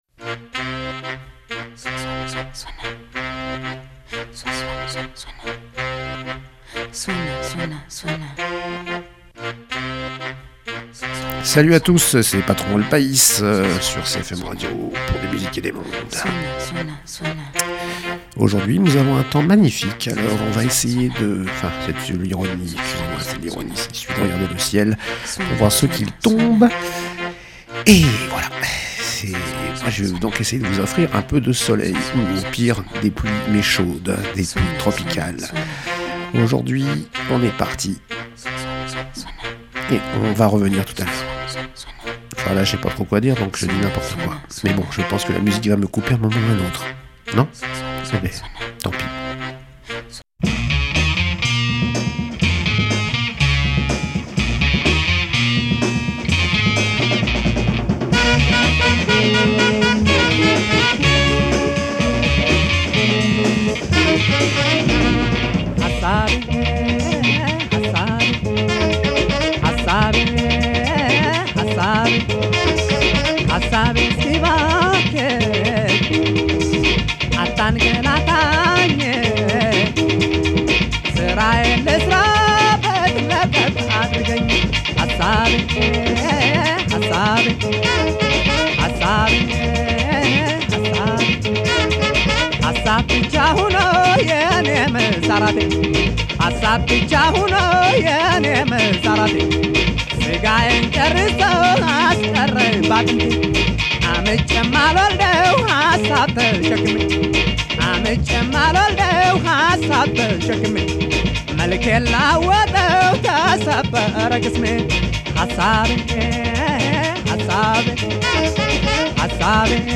De l’Ethiopie à l’Afrique du Sud, en passant par le Nigéria et une dédicace à Jean Pierre Marielle disparu cette semaine. Le jazz Éthiopien, ou plus communément appelé Éthio-jazz est une forme de jazz apparue à la fin des années 50, originaire d’Ethiopie et d’Érythrée.